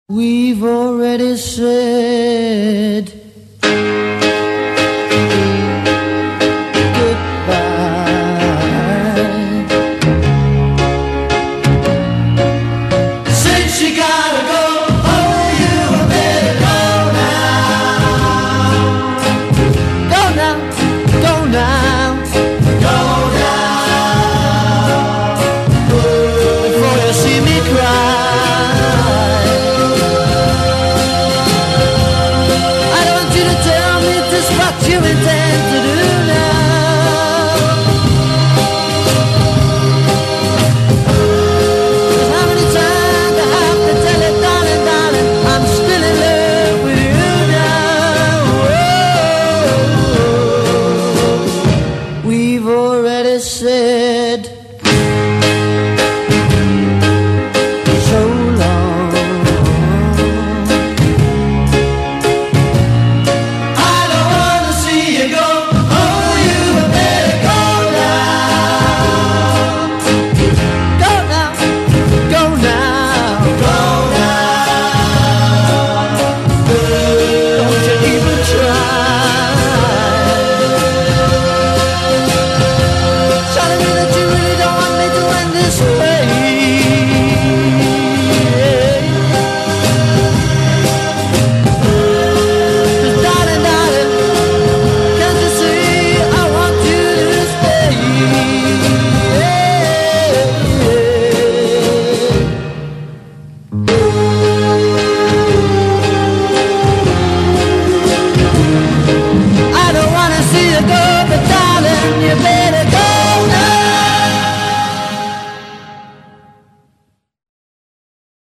close harmonies